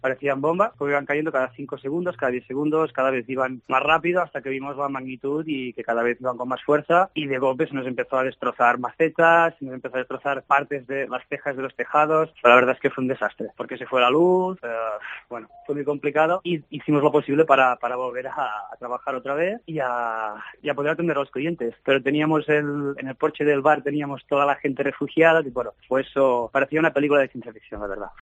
DECLARACIONES DE VECINOS DE LA BISBAL DE L'EMPORDÀ